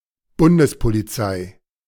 The Federal Police (German: Bundespolizei, [ˈbʊndəspoliˌtsaɪ]